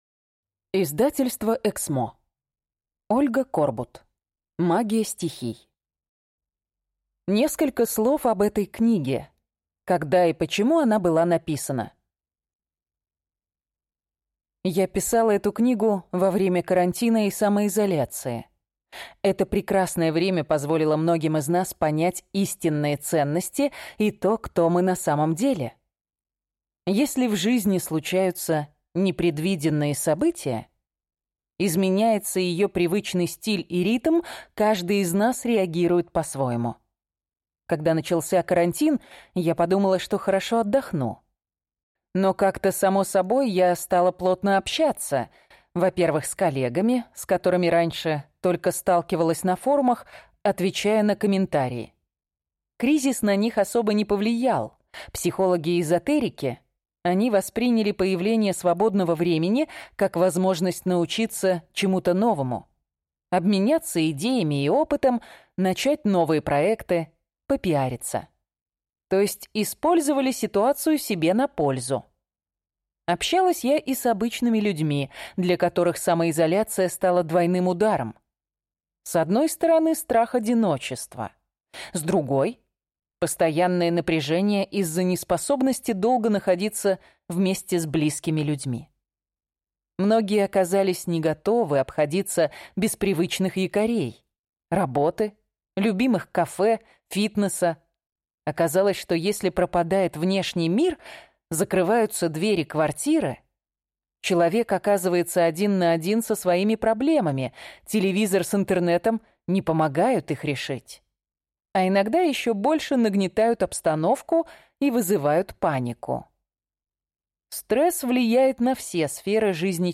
Аудиокнига Магия стихий. Как использовать силы природы, чтобы получить поддержку и защиту | Библиотека аудиокниг